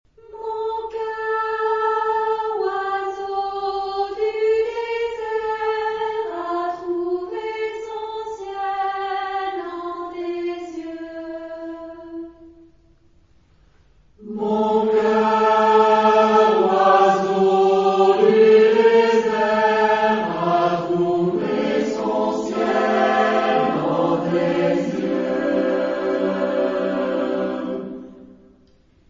Genre-Style-Form: Secular ; Poem
Mood of the piece: moving ; gentle
Type of Choir: SATB  (4 mixed voices )
Tonality: D minor